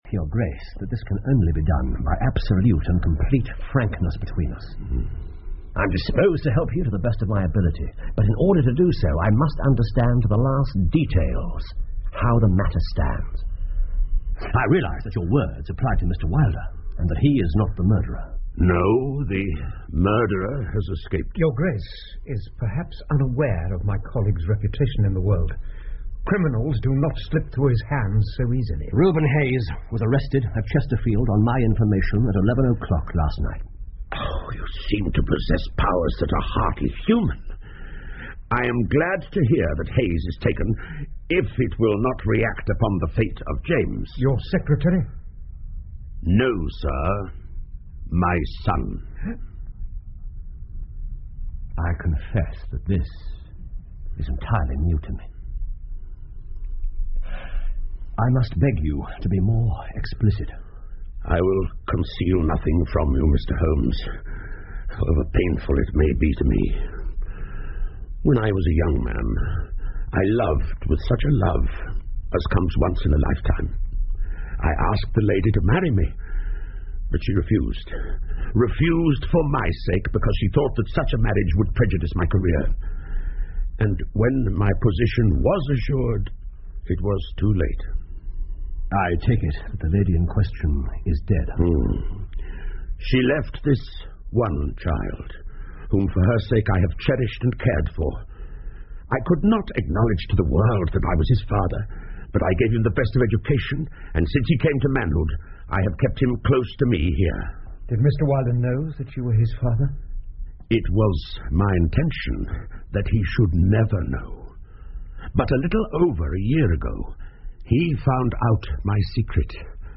福尔摩斯广播剧 The Priory School 8 听力文件下载—在线英语听力室